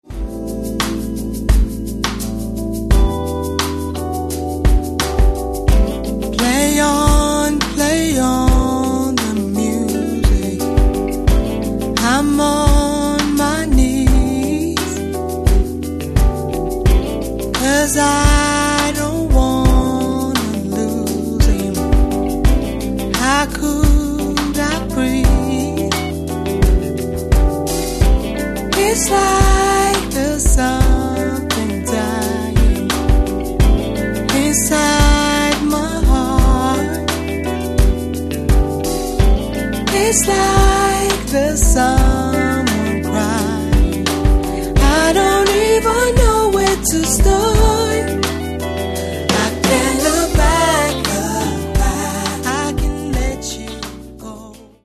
Каталог -> Поп (Легкая) -> Подвижная